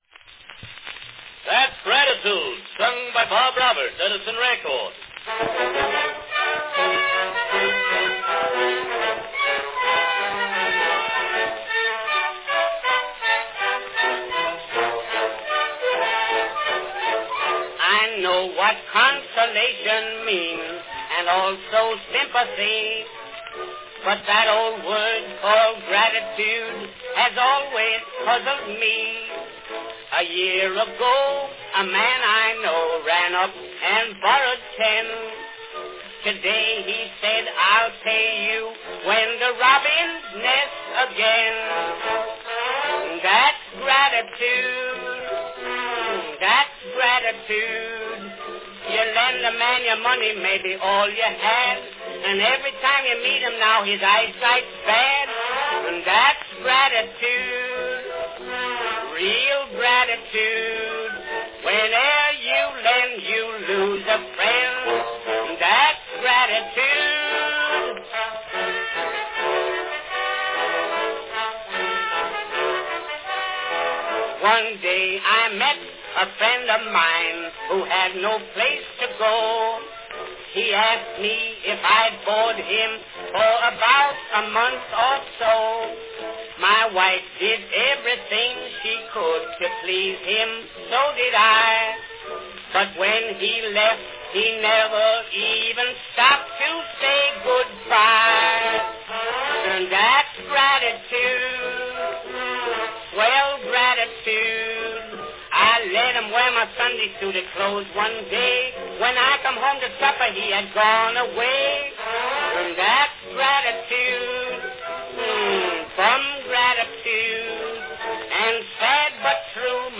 A fun comic song from 1907
Category Song